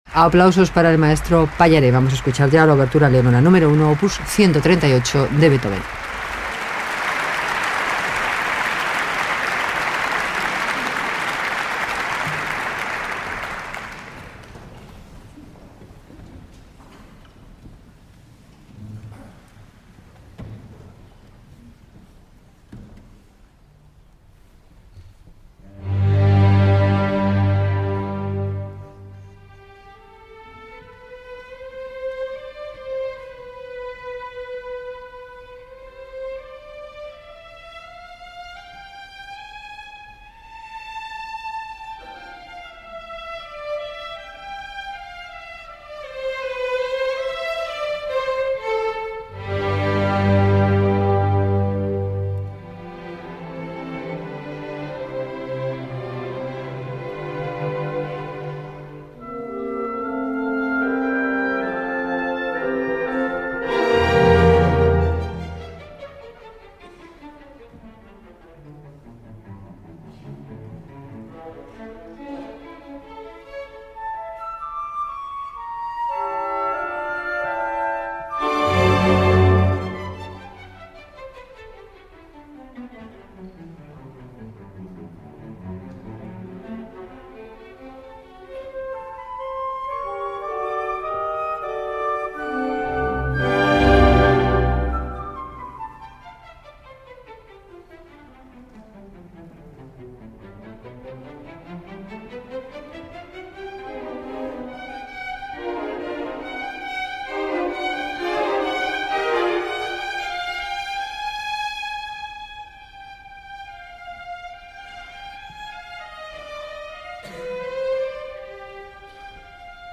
Transmisión directa desde el Teatro Monumental de Madrid.
Concierto para acordeón y orquesta.